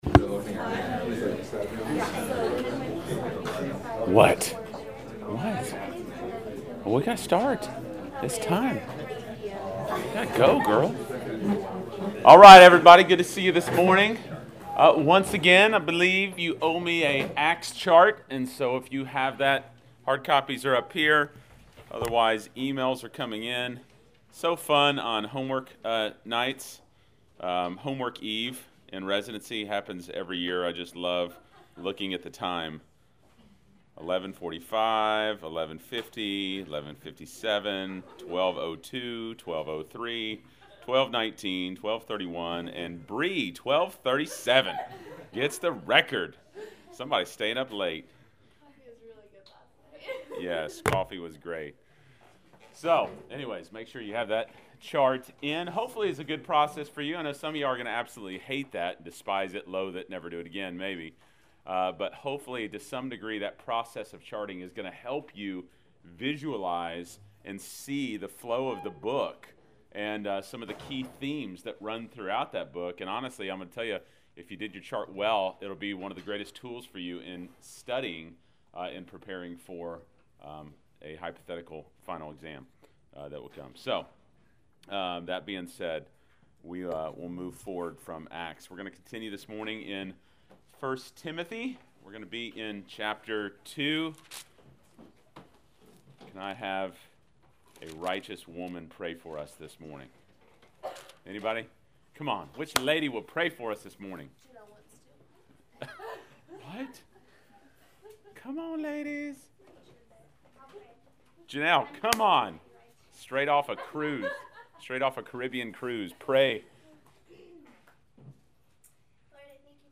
Class Session Audio December 01